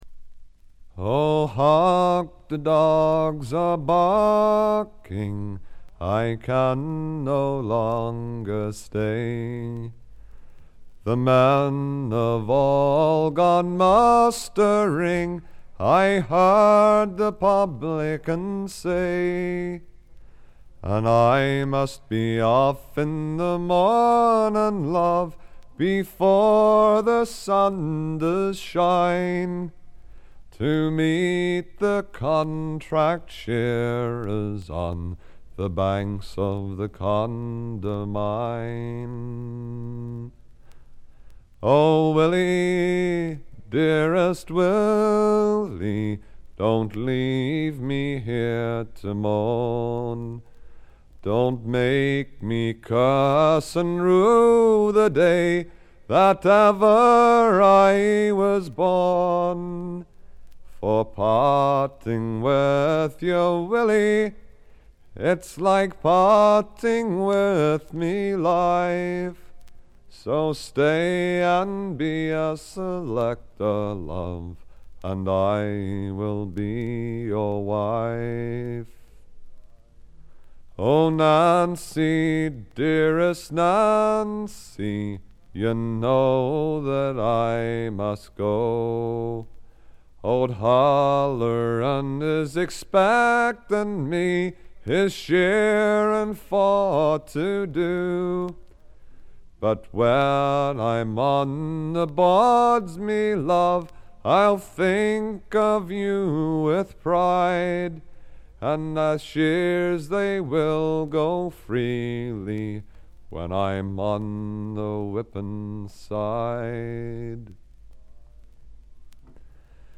B1、B2でちょっと気になるチリプチ。
トラッド基本盤。
試聴曲は現品からの取り込み音源です。